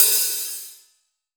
ROCK OP HH.WAV